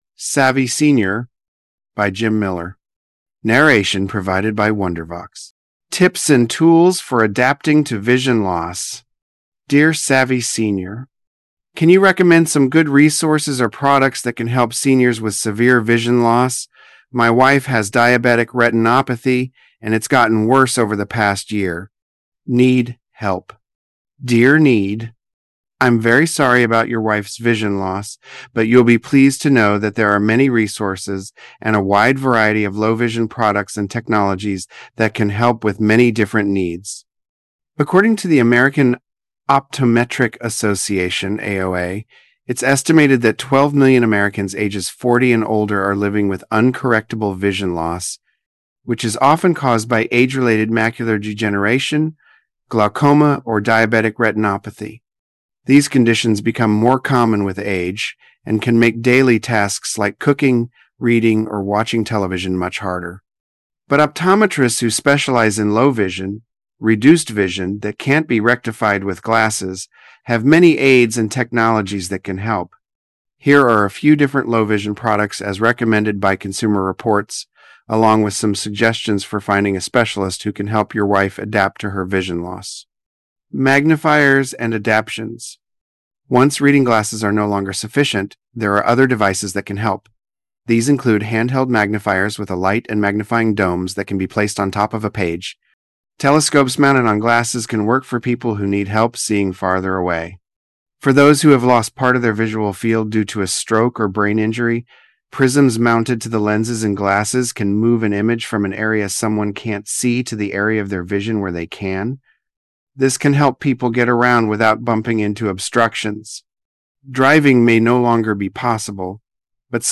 Narration provided by Wondervox